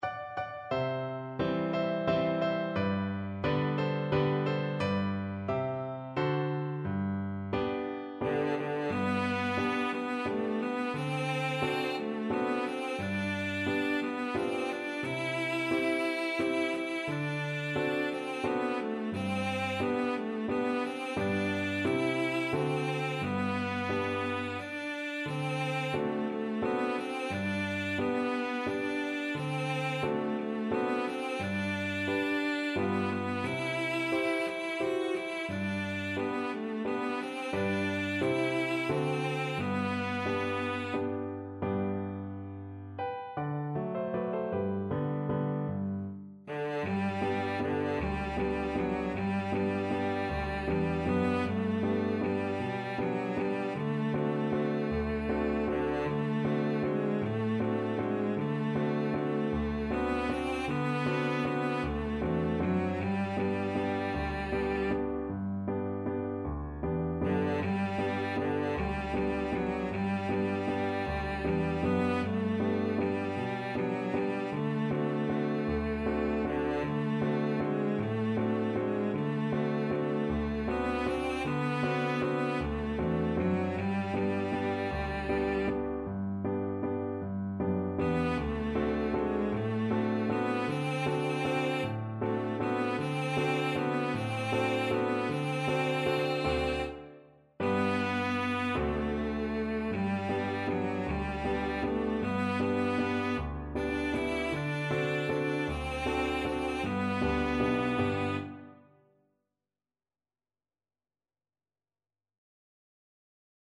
3/4 (View more 3/4 Music)
Slow =c.88
Traditional (View more Traditional Cello Music)